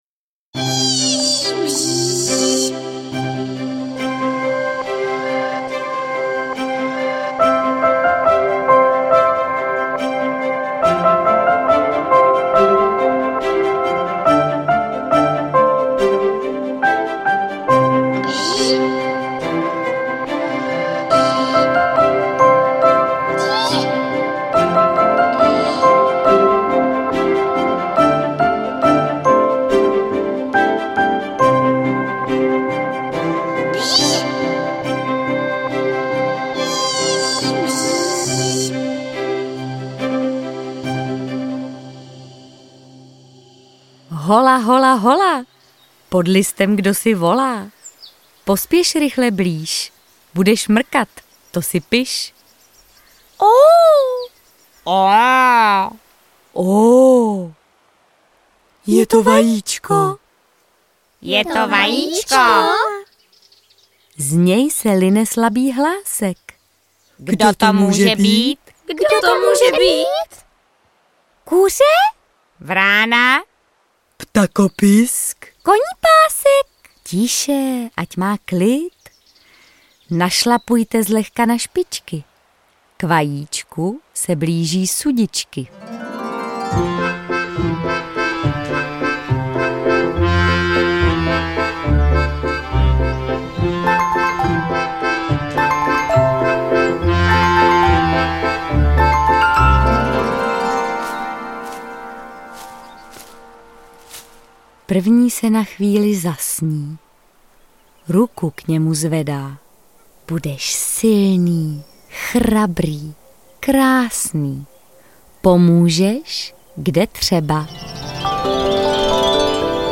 Ó, ó, ó, vajíčko! audiokniha
Audiokniha Ó, ó, ó, vajíčko! obsahuje veršovaný příběh z hmyzí říše.